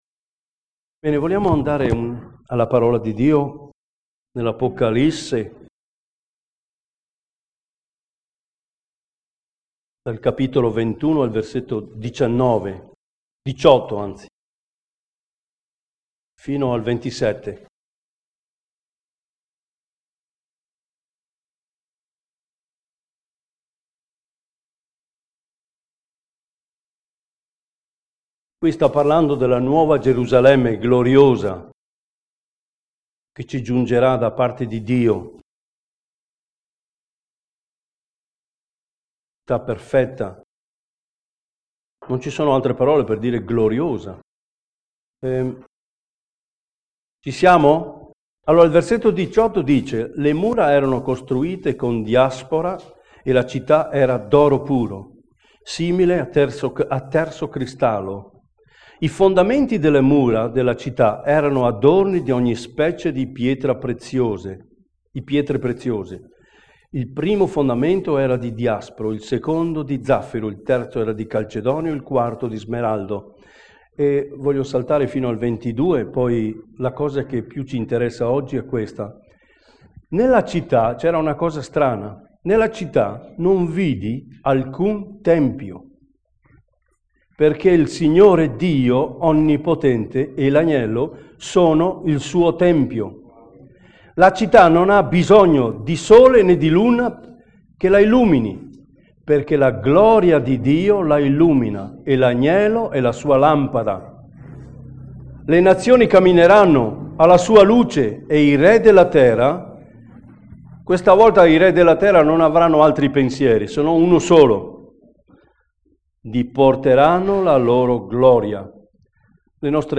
Predicazione 17 giugno 2012 - Nella nuova Gerusalemme il tempio � Dio stesso